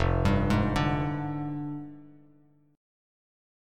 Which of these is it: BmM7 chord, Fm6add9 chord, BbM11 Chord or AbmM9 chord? Fm6add9 chord